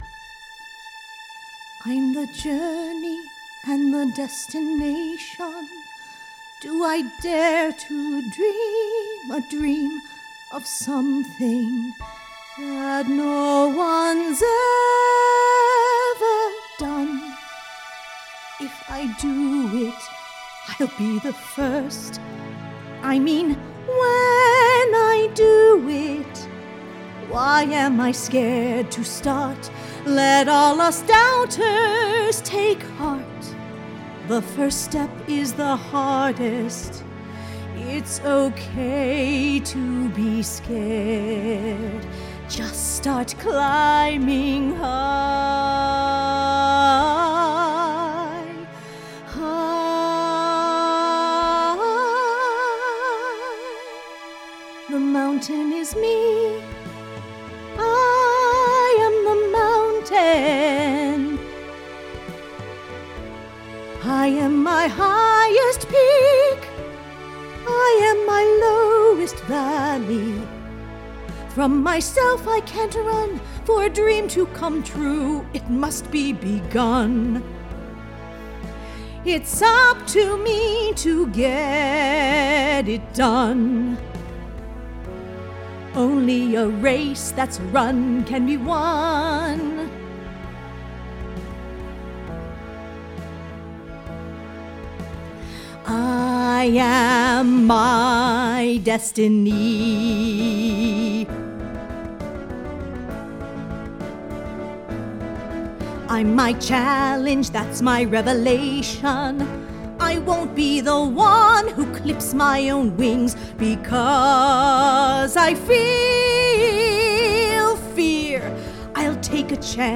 First (rough) demo for “The Mountain is Me” with the role of Poppy
TMIS - vocals and orch w mix (mp3) Download
TMIS - vocals and orch w mix.mp3